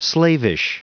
Prononciation du mot slavish en anglais (fichier audio)
Prononciation du mot : slavish